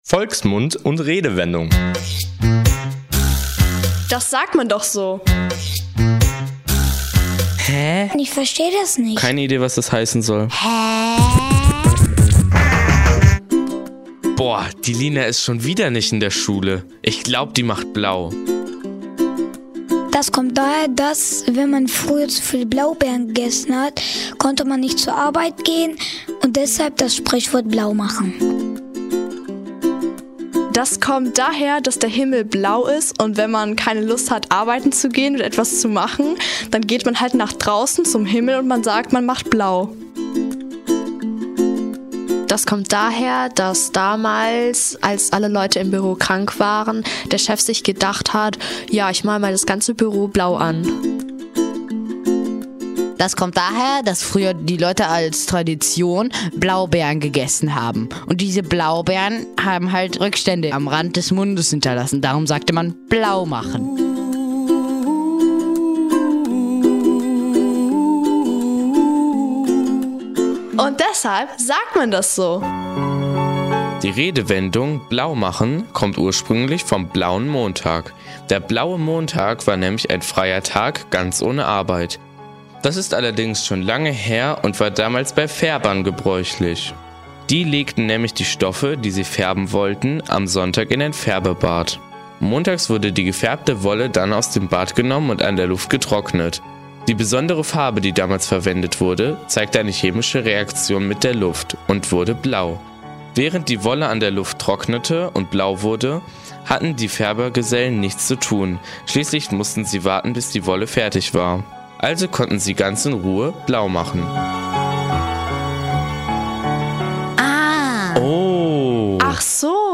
Unsere Sendungen könnt ihr im Bürgerfunk auf Radio 90,1 und in unserer Mediathek verfolgen.